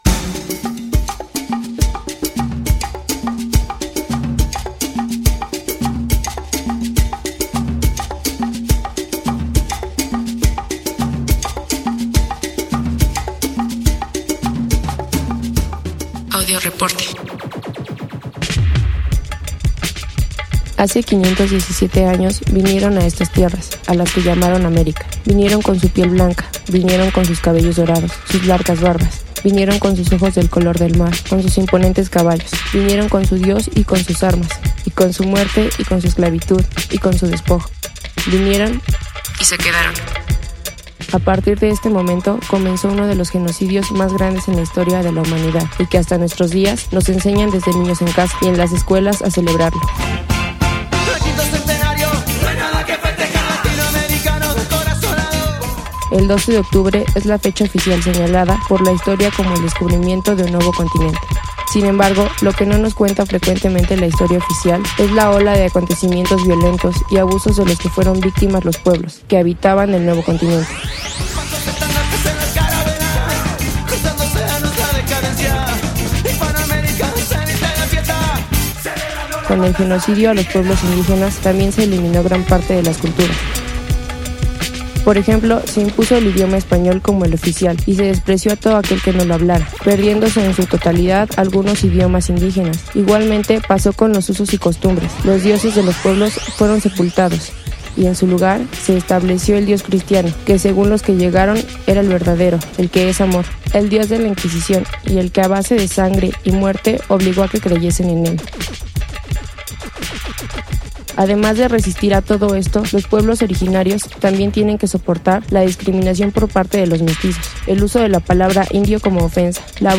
Testimonios de asistentes a la Fiesta de los pueblos indios en Puebla